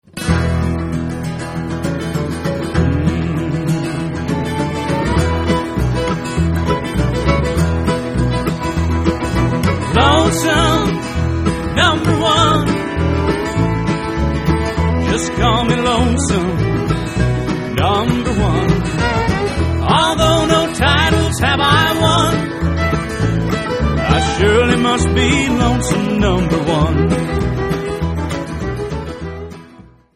inimitable brother harmonies
Acoustic
Americana
Bluegrass
Country
Folk & Traditional